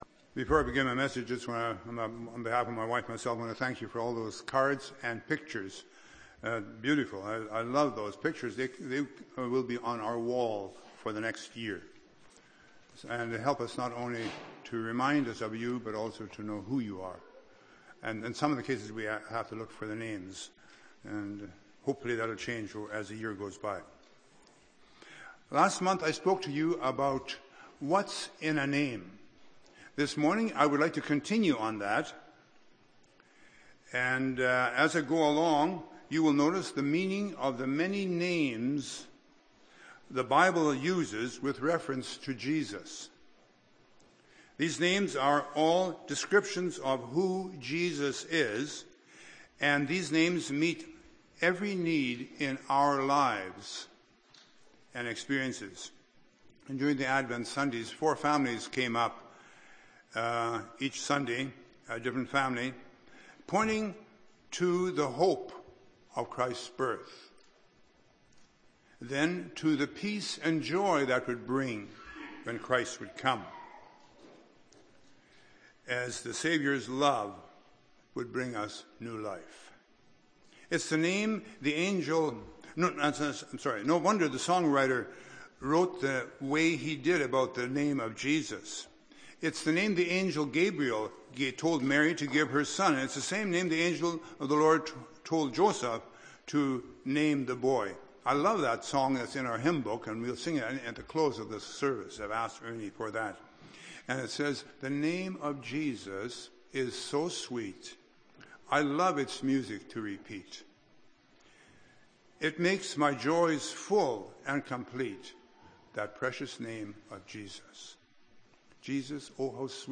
Dec. 25, 2011 – Sermon